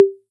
python-drum-sequencer/samples/808/014.wav at master